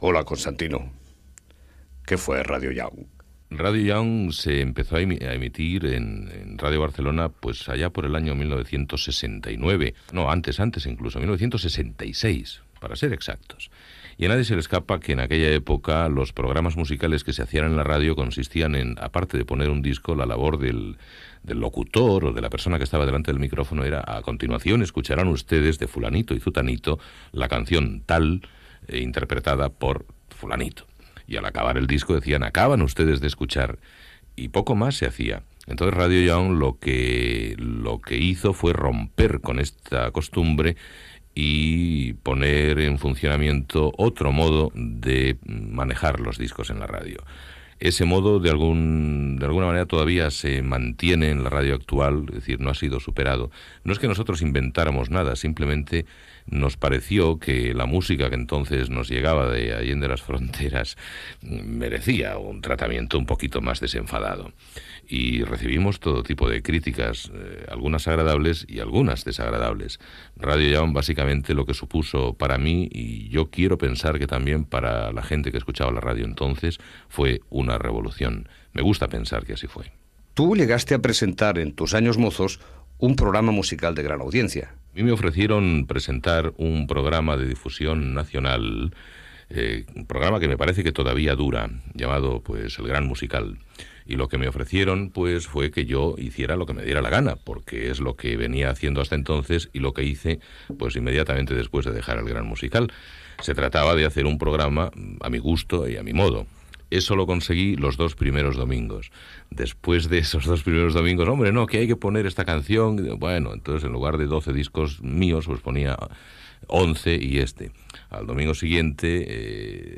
Entrevista a Constantino Romero pel seu programa "Radio Young" a Ràdio Barcelona